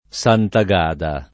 Santagada [ S anta g# da ] cogn.